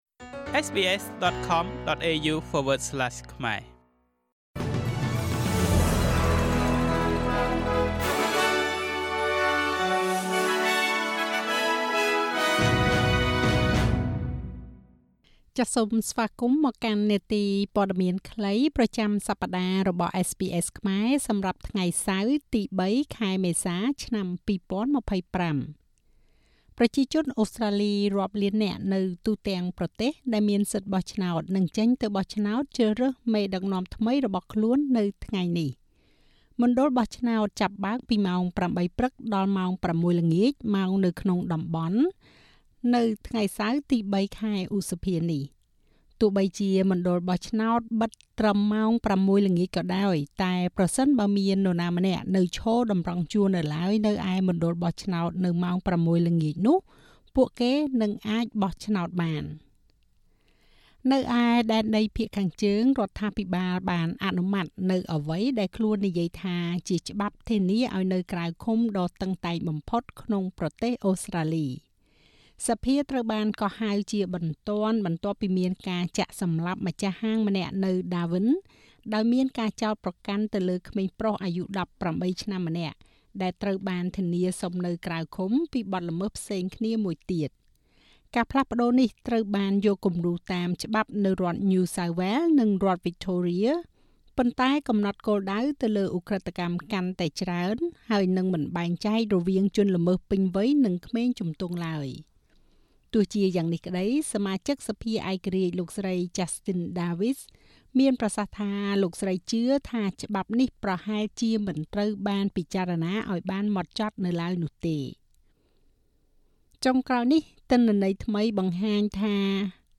នាទីព័ត៌មានខ្លីប្រចាំសប្តាហ៍របស់SBSខ្មែរ សម្រាប់ថ្ងៃសៅរ៍ ទី៣ ខែឧសភា ឆ្នាំ២០២៥